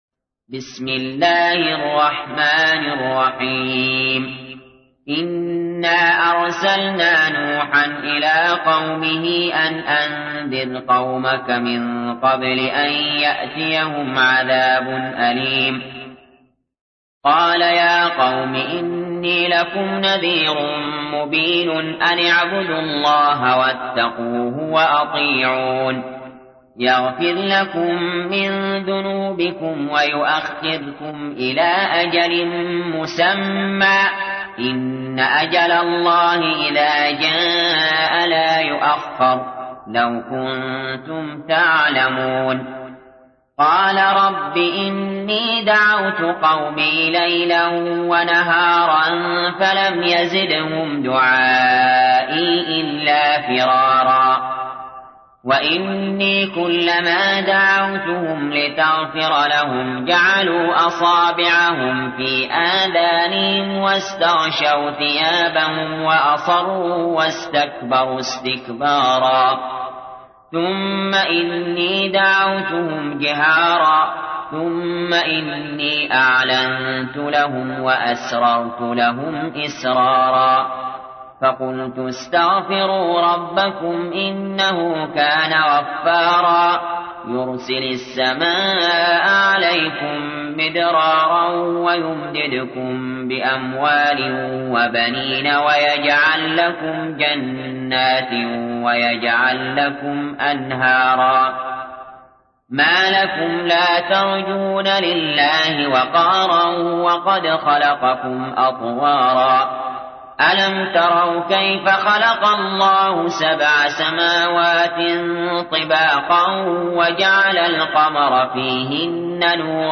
تحميل : 71. سورة نوح / القارئ علي جابر / القرآن الكريم / موقع يا حسين